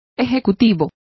Complete with pronunciation of the translation of executive.